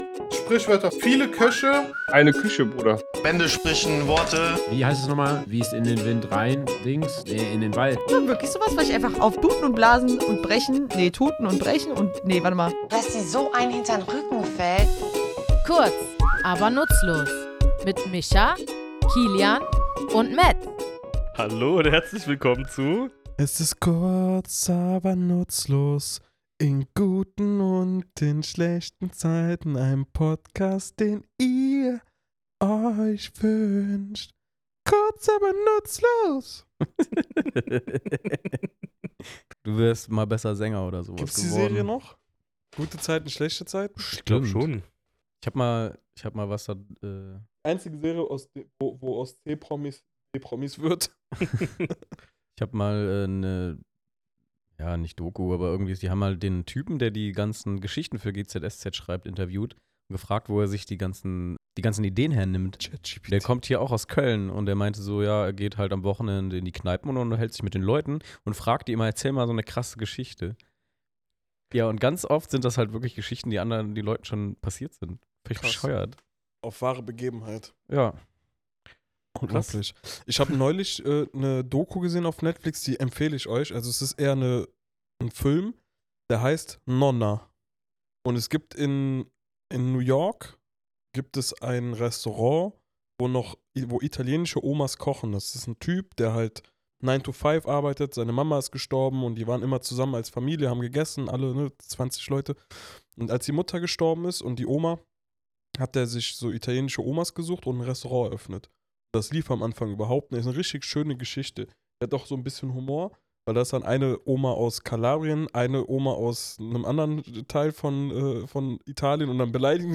Wir, drei tätowierende Sprachliebhaber, schnacken in unserem Tattoostudio über die Herkunft dieser kuriosen Formulierung, die von einem historischen Braumeister bis zu volkstümlichen Redensarten reicht.